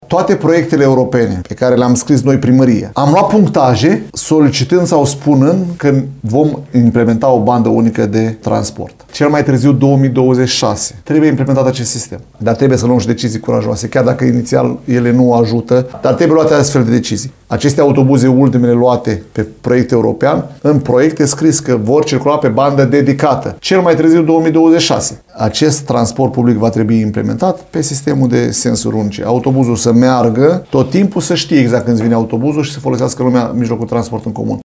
El a declarat postului nostru că autobuzele TPL vor circula pe bandă dedicată, aceasta fiind una dintre obligațiile municipalității în proiectele cu finanțare europeană.